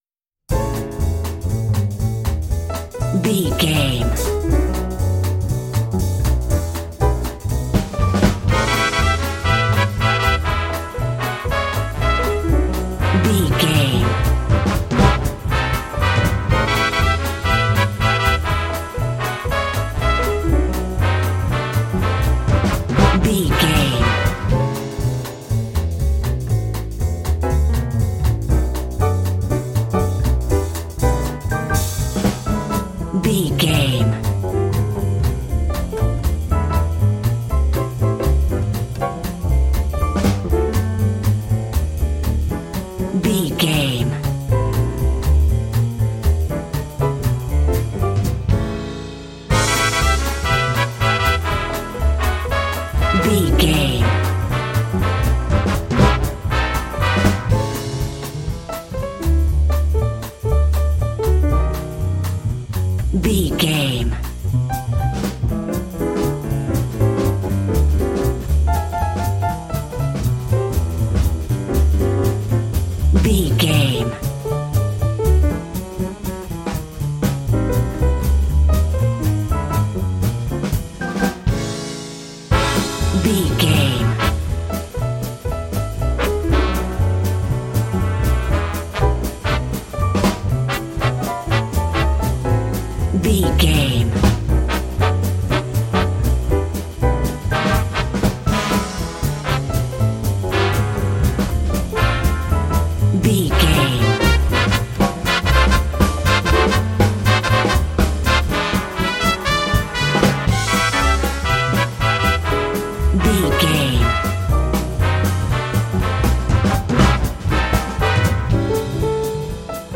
Ionian/Major
Fast
energetic
groovy
piano
electric guitar
brass
drums
double bass
bebop swing
jazz